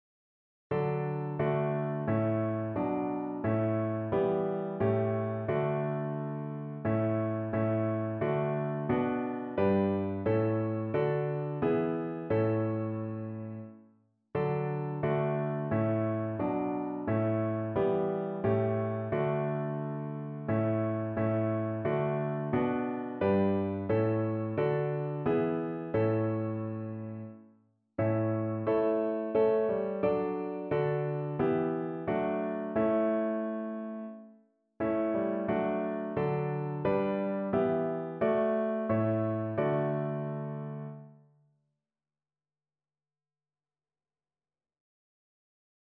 Lob und Anbetung
Notensatz 1 (4 Stimmen gemischt)